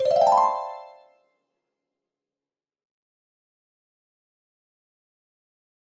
achievement.wav